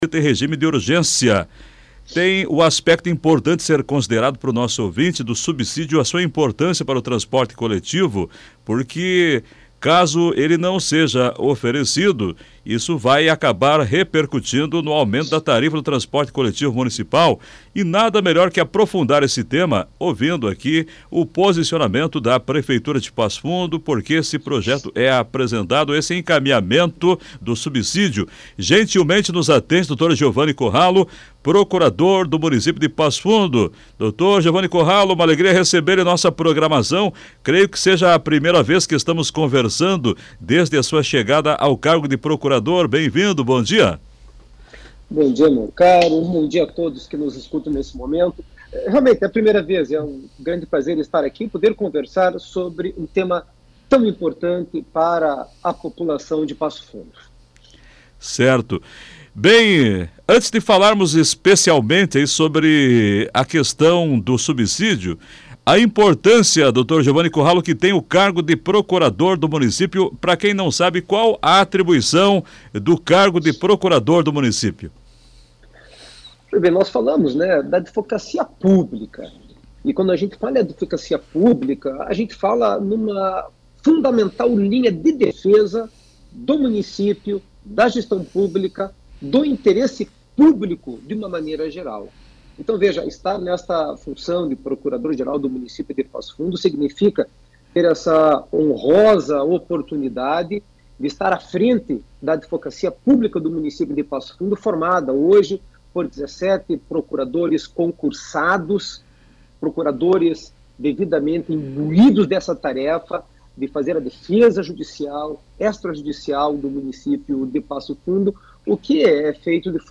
A Rádio Planalto News (92.1) realizou entrevista com o Procurador-geral do Município, Giovani Corralo.